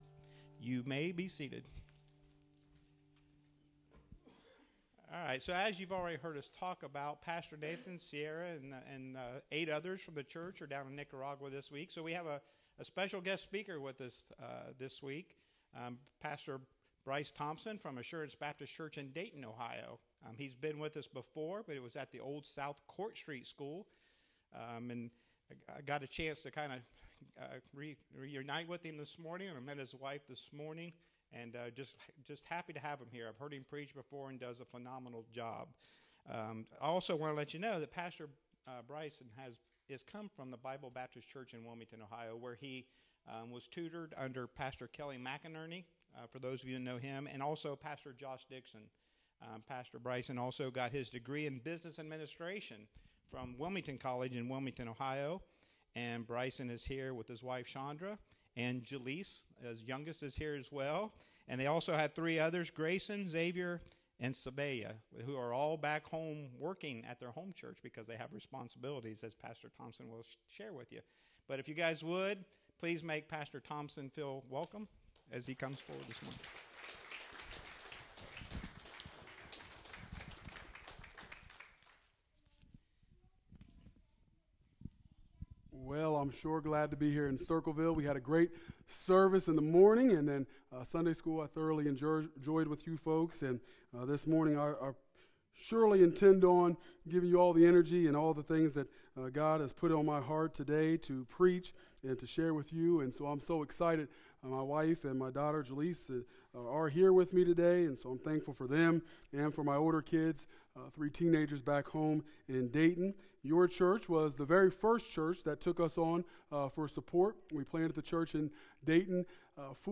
Sunday morning, February 5, 2023.